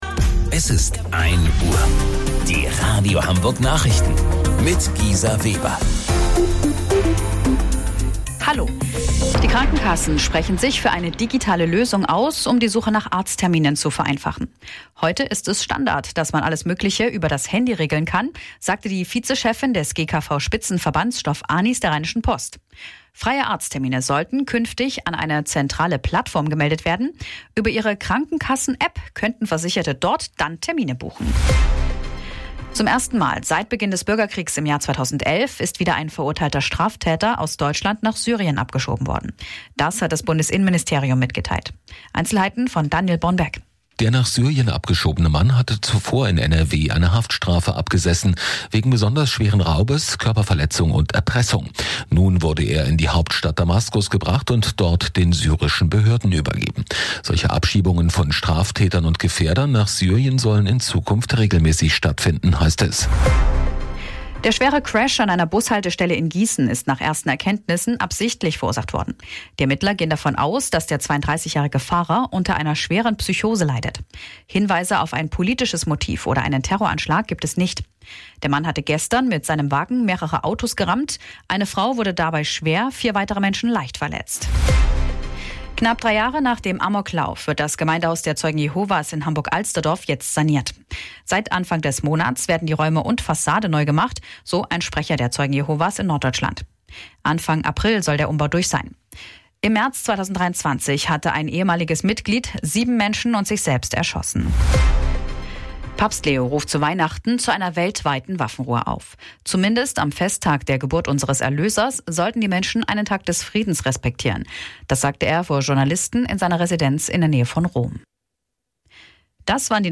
Radio Hamburg Nachrichten vom 24.12.2025 um 01 Uhr